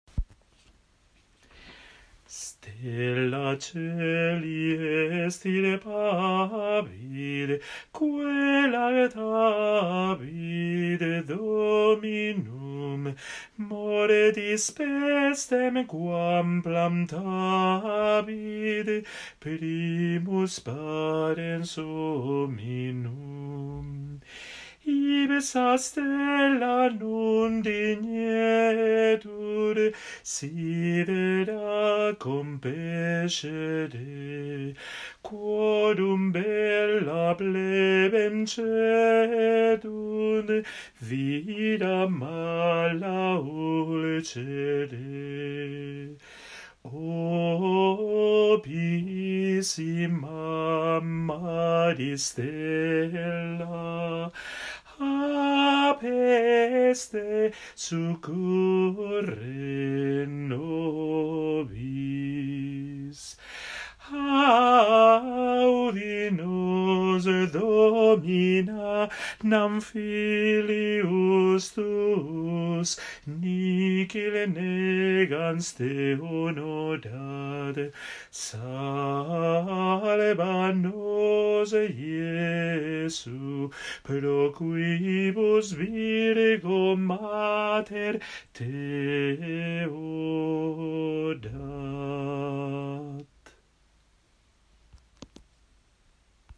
antífona contra la peste , Stella cæli extirpavit (antífona) , Francisco Gonzaga